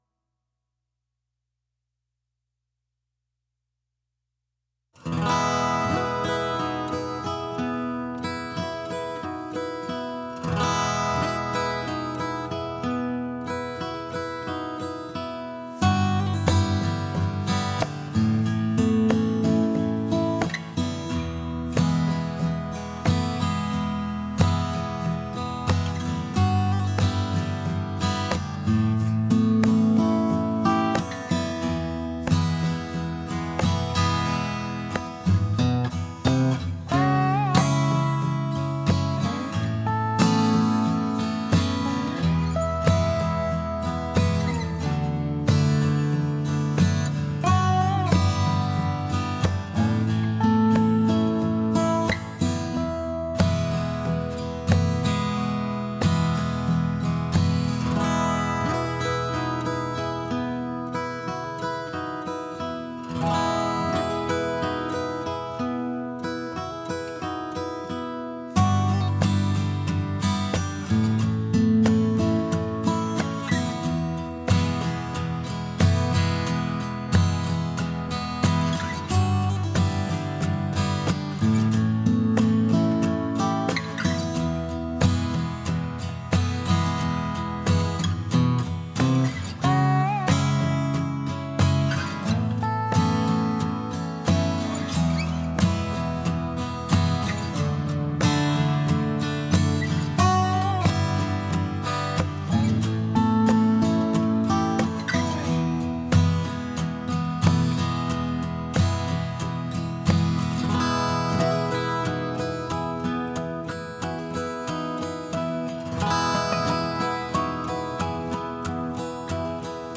Атмосферная без ограничений авторского права